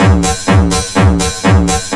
FXBEAT08-L.wav